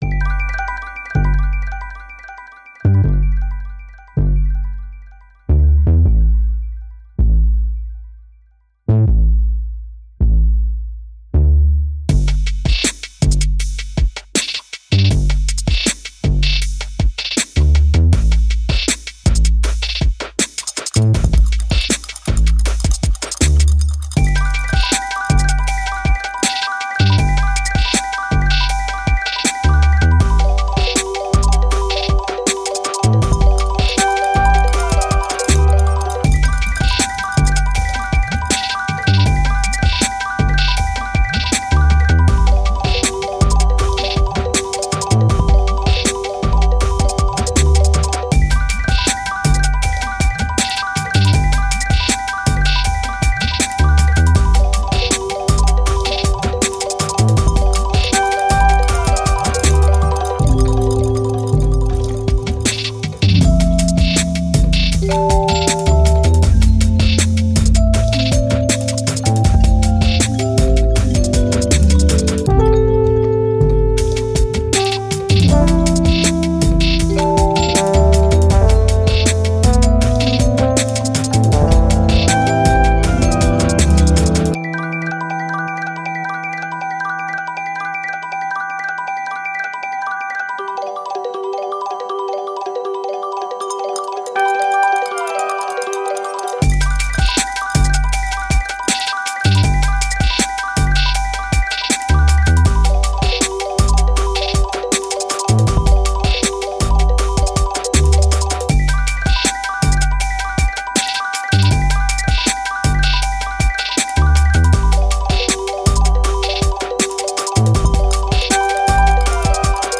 Electronic, Quirky, Playful